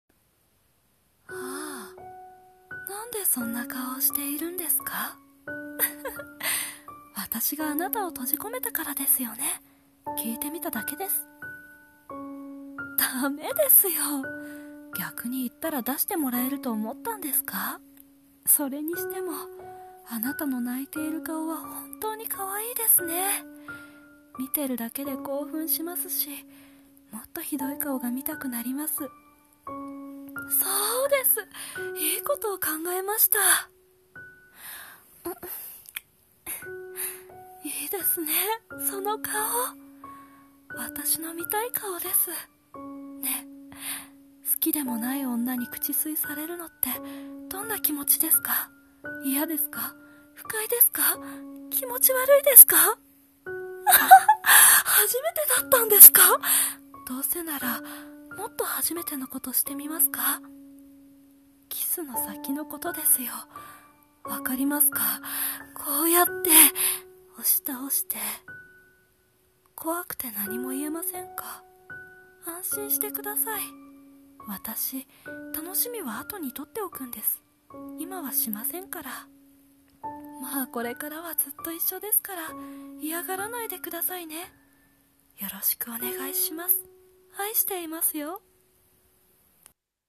【声劇台本】一方的な愛
胡蝶しのぶ(声真似)